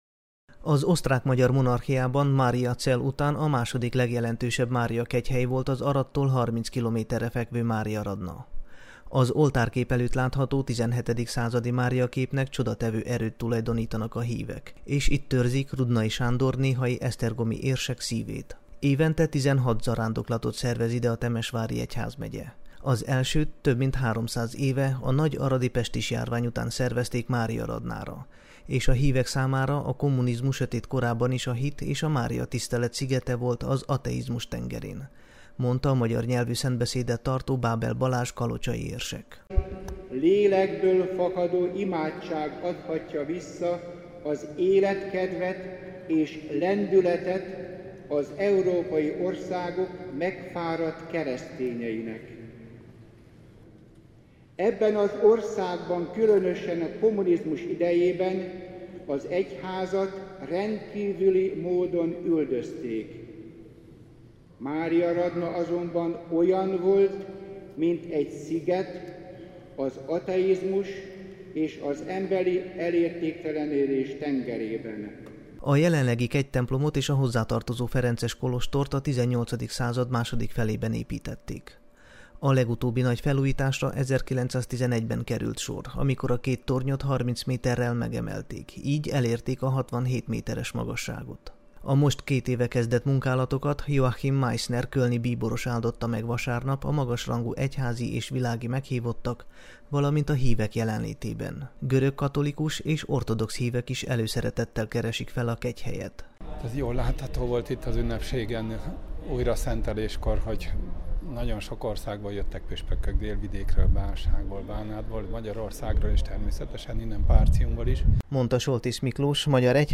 A szentbeszédet Joachim Meisner kölni bíboros tartotta, magyarul koncelebrált Bábel Balázs kalocsai érsek.
A német, magyar és román nyelvű szentmise végén megáldották a templomot és a rendházat.